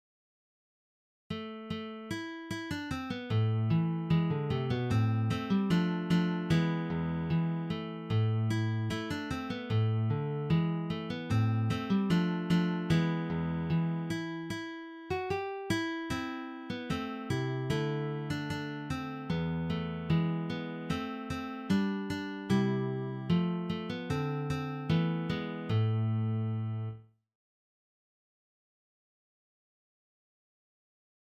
leicht arrangiert für Gitarre
Sololiteratur
Gitarre (1)